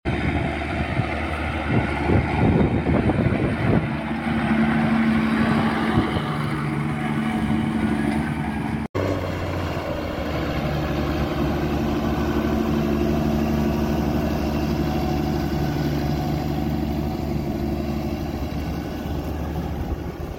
Engine sound Claas Arion 640 sound effects free download